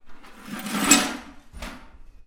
移动垃圾
描述：一个人试图将垃圾扔进垃圾桶，无意间射入一堆玻璃瓶（电池）中用ZOOM H6录制
Tag: 芯片 垃圾桶 的PERC 浮渣 造纸 食品 垃圾